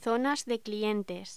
Locución: Zona de clientes
voz